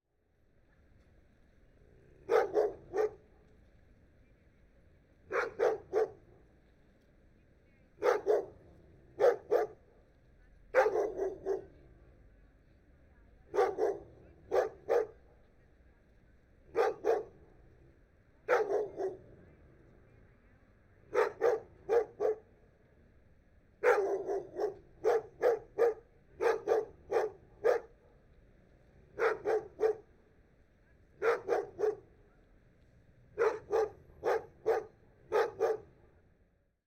dog-dataset
dogs_0026.wav